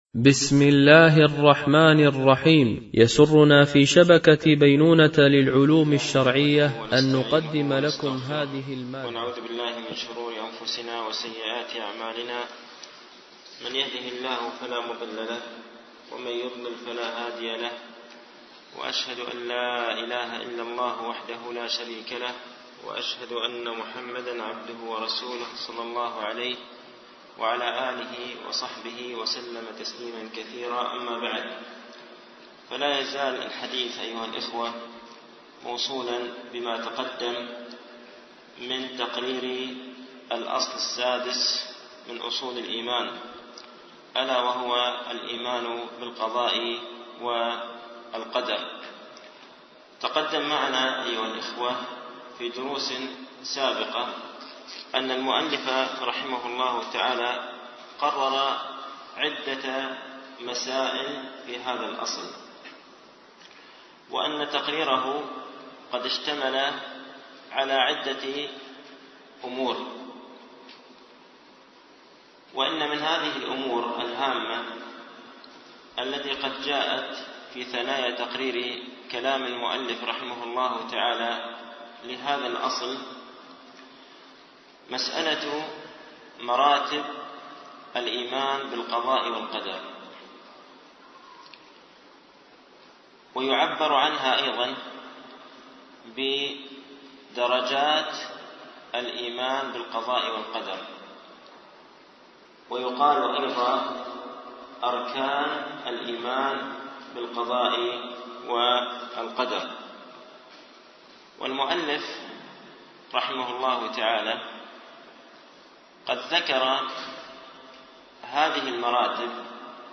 شرح مقدمة ابن أبي زيد القيرواني ـ الدرس الحادي و العشرون